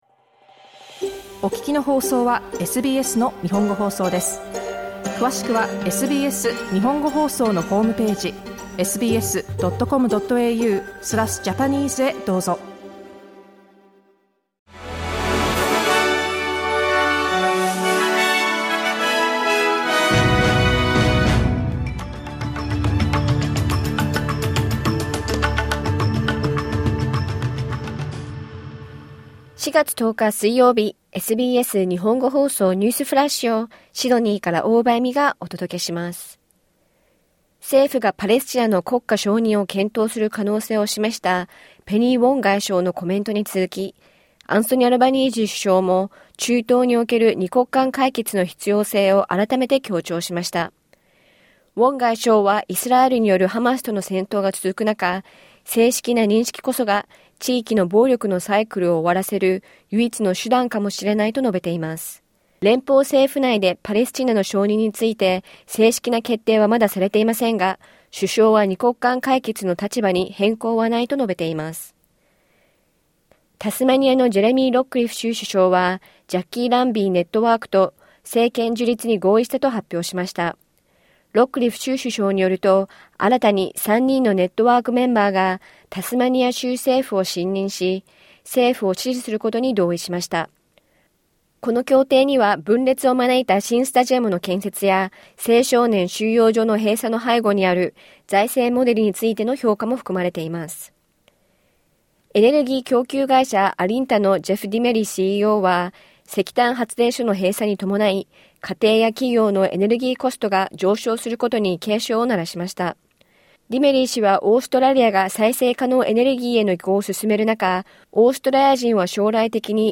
SBS日本語放送ニュースフラッシュ 4月10日水曜日